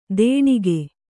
♪ dēṇige